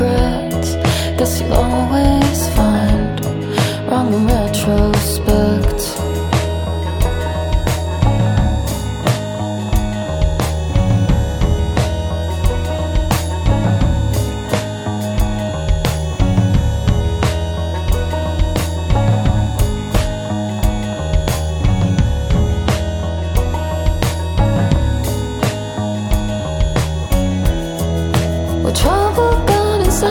De retour en formation trio